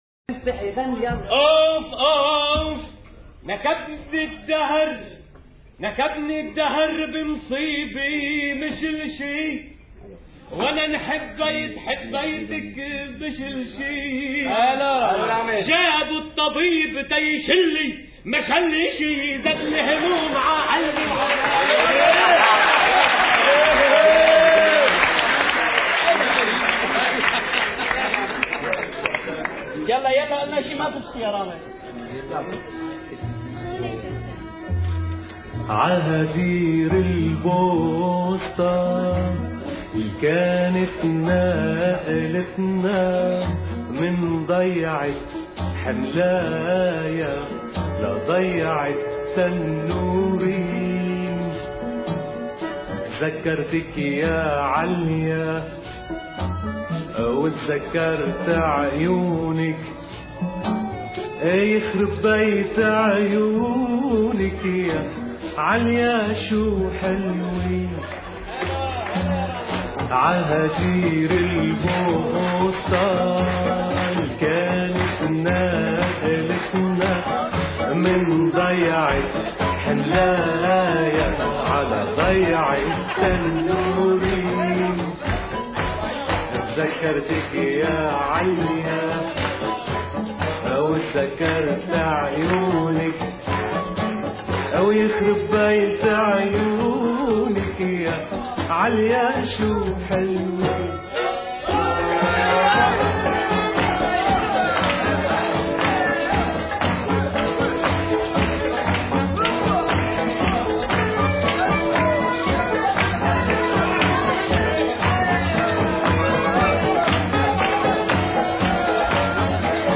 اغاني لبنانيه